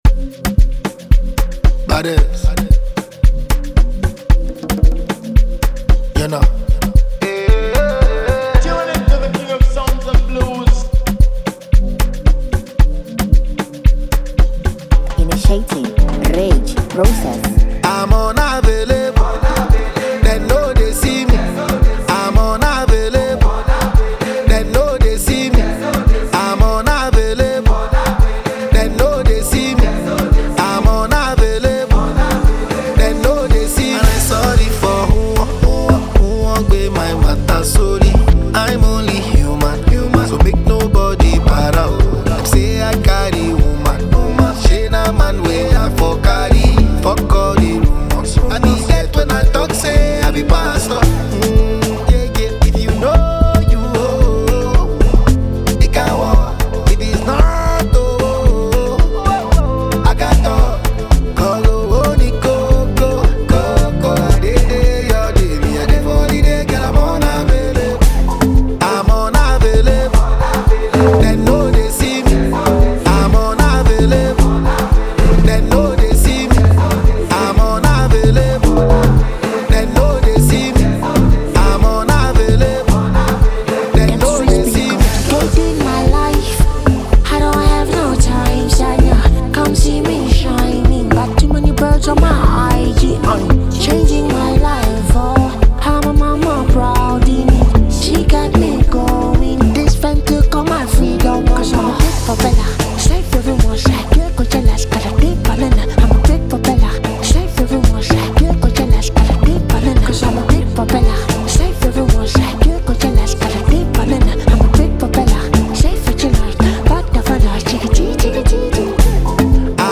smooth vocals and the catchy hook
blends Afrobeat with contemporary sounds
With its upbeat tempo and catchy sounds